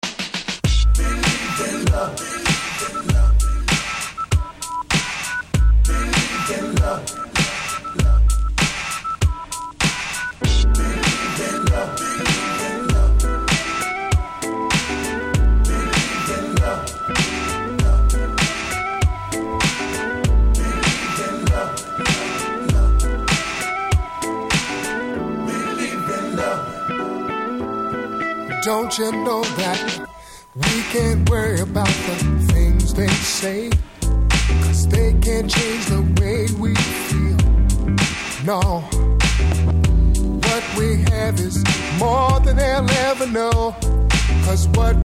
Nice 90's R&B !!
しっかりしたBeatに甘い歌声、Urbanな上モノと一切隙の無いProduction。